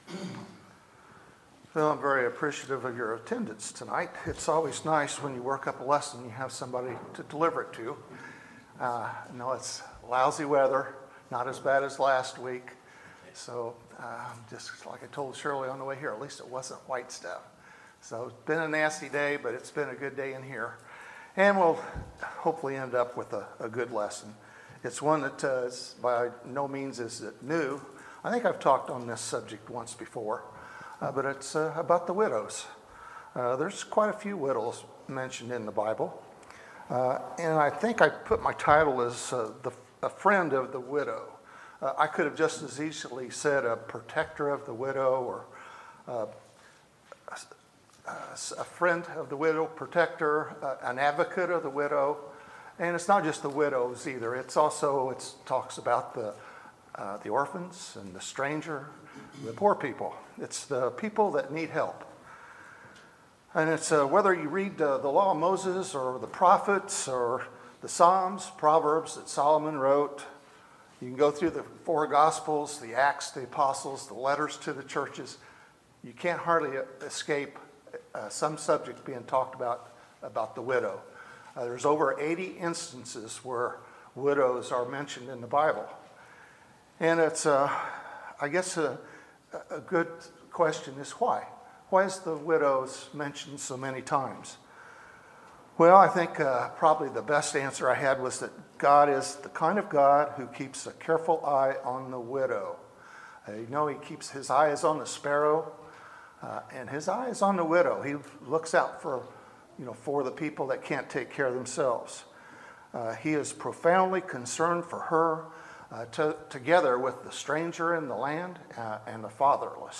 Sermons, November 12, 2017